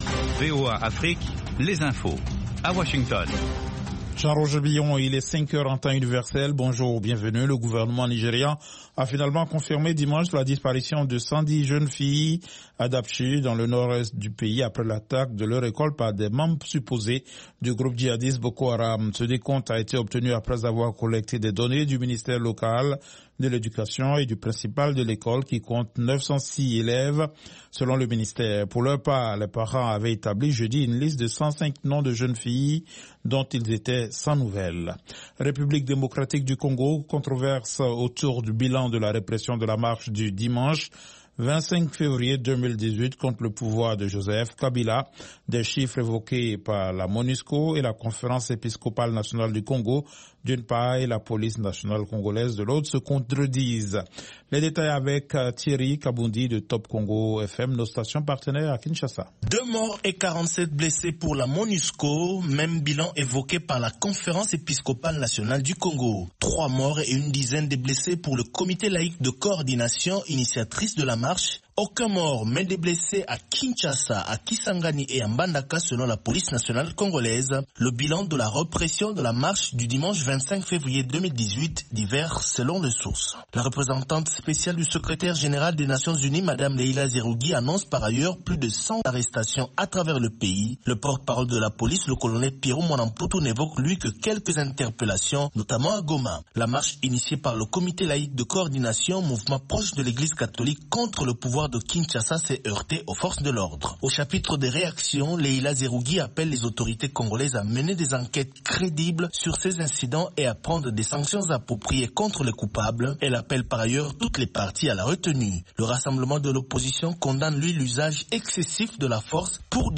5 min News French